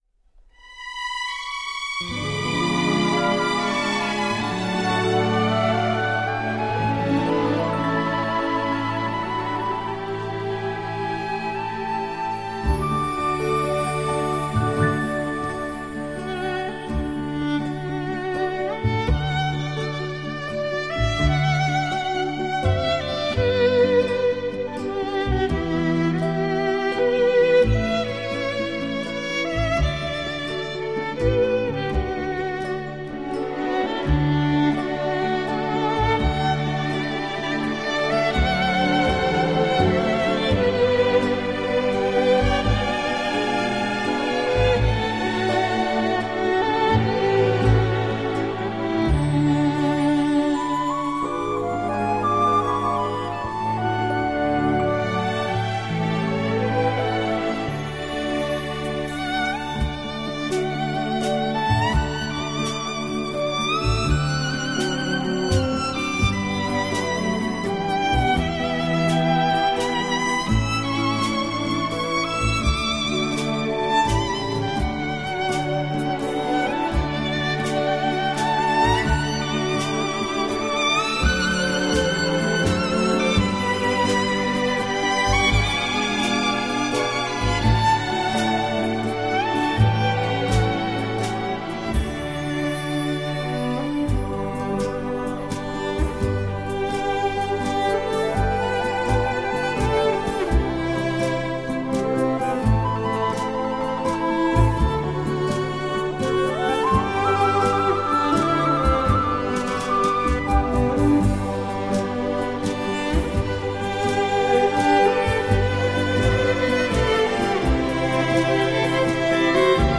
风靡全球的世界经典金曲，曲曲抒情，感人至深。